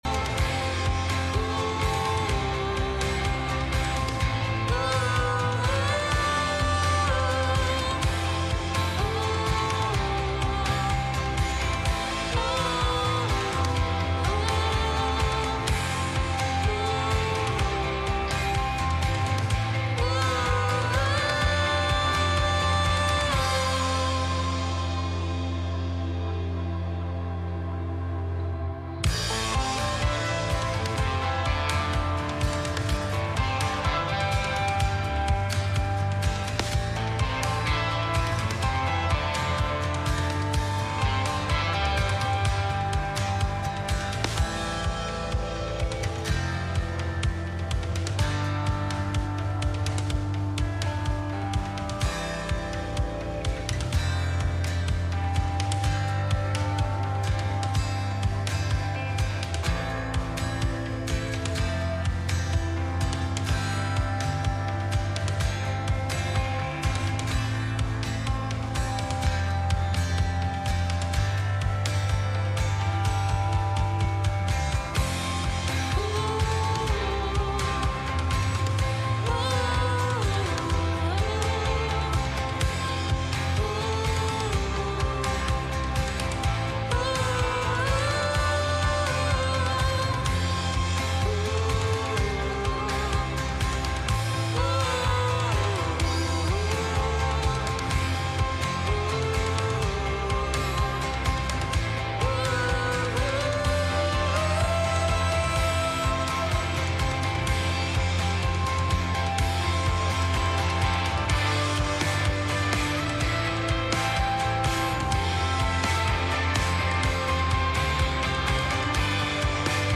Study Of Ester Service Type: Midweek Meeting https